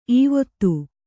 ♪ īvottu